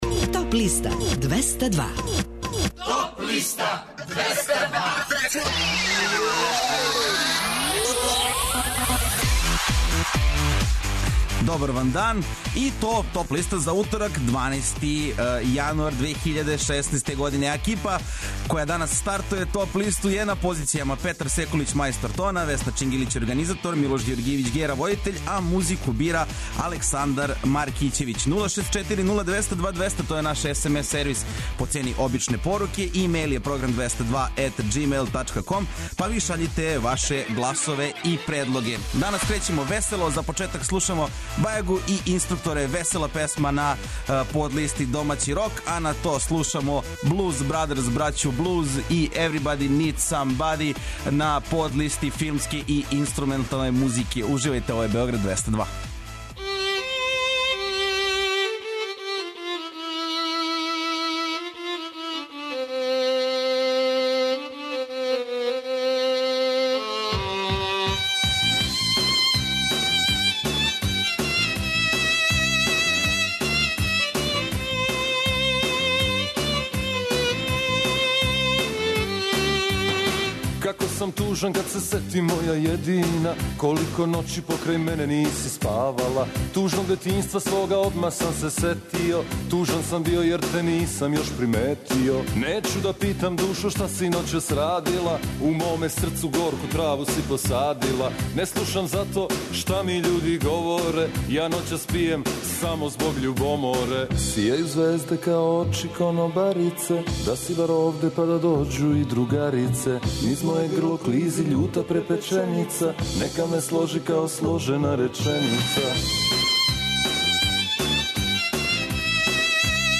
Емисија садржи више различитих жанровских подлиста. Оне чине листу програма која има 202 места.